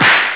Sons de humor 47 sons
chicote2.wav